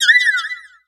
Cri de Fluvetin dans Pokémon X et Y.